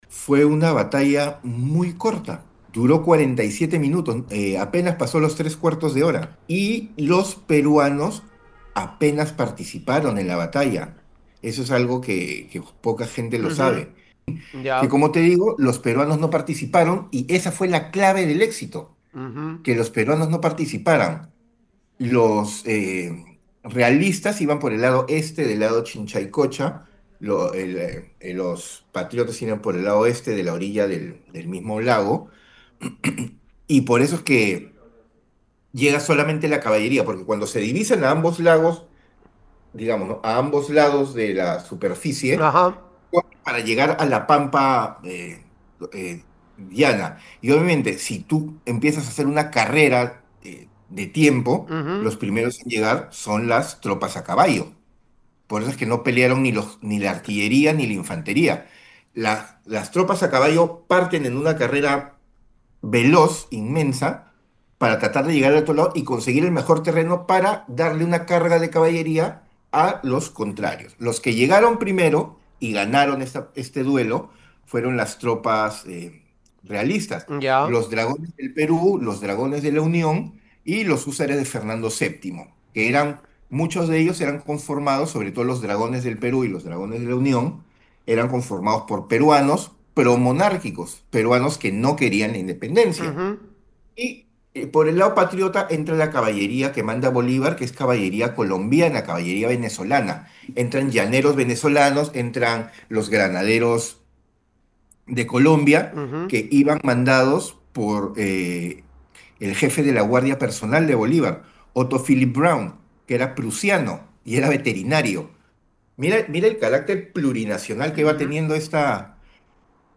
en conversación con Radio Uno destacó un episodio crucial que inclinó la balanza en la gesta independentista de América Latina